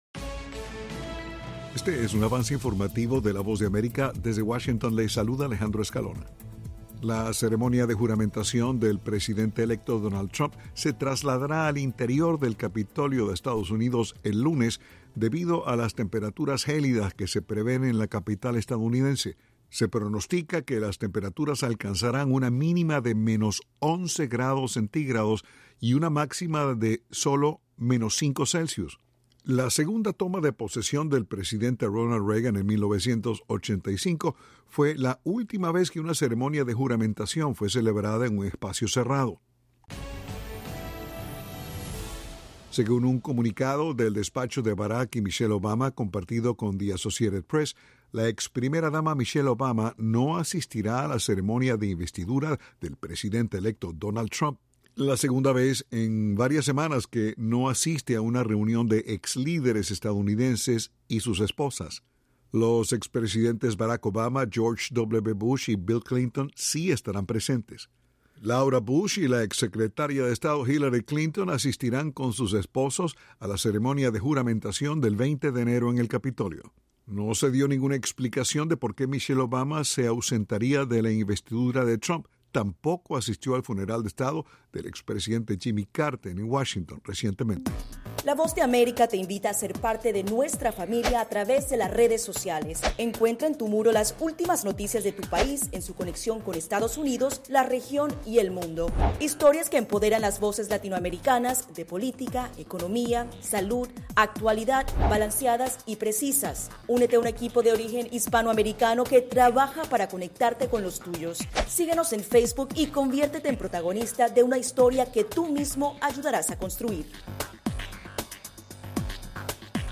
Avance Informativo
El siguiente es un avance informativo de la Voz de América.